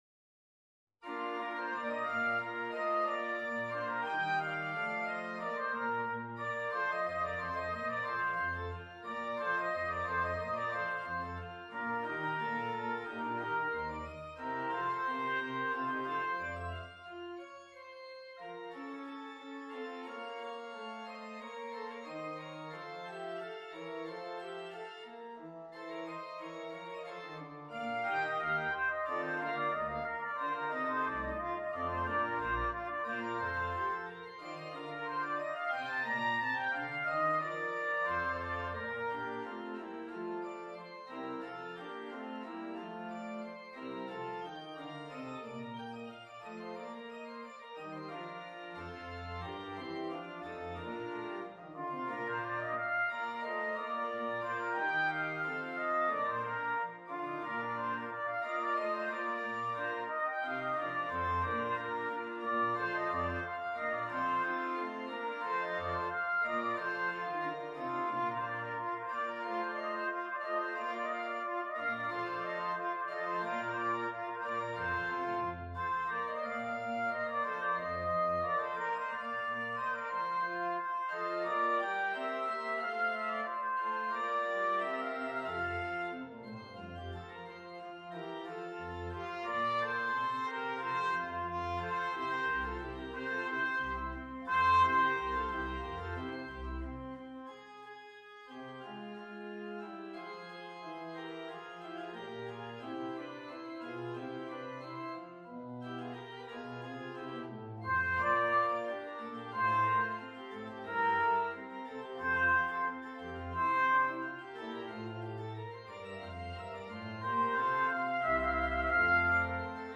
Trumpet in C Trumpet in Bb Organ
Instrument: Trumpet
Style: Classical